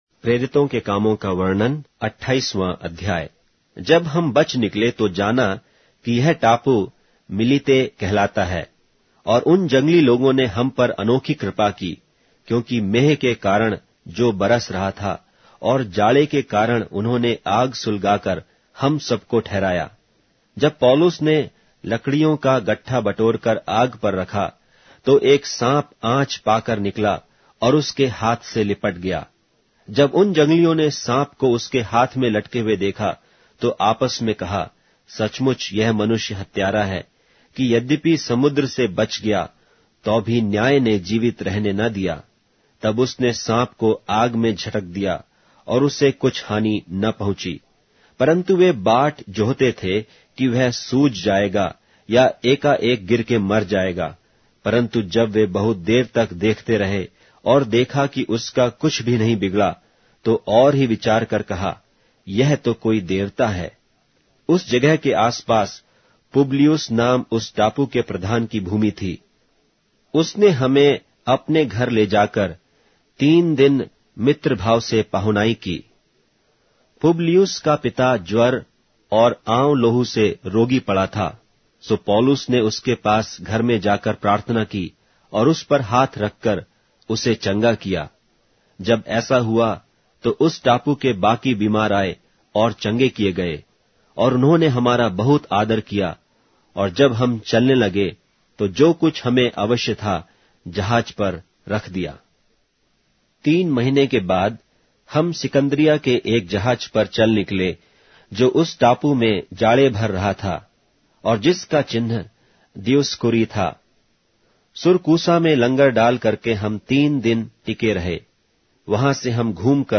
Hindi Audio Bible - Acts 14 in Ervpa bible version